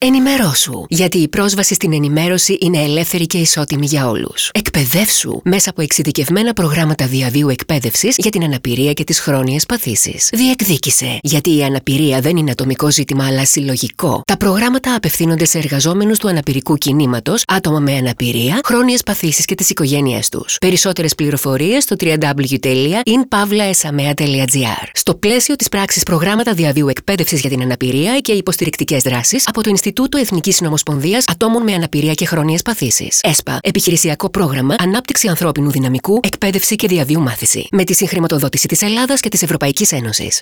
H αφίσα της Πράξης (2.66 MB) Ραδιοφωνικό σποτ σε μορφή wav (5.59 MB) Ενημερωτικό Φυλλάδιο σε μορφή Pdf (10.37 MB) Τηλεοπτικό σποτ με Νοηματική και Υποτιτλισμό σε μορφή mp4 (13.69 MB)